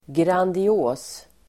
Ladda ner uttalet
Uttal: [grandi'å:s]